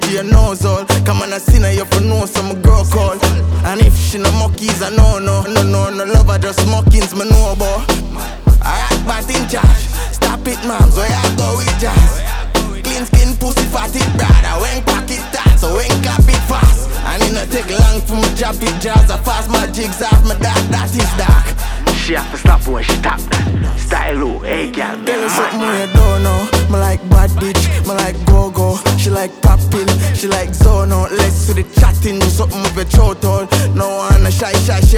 Жанр: Танцевальная музыка
# Modern Dancehall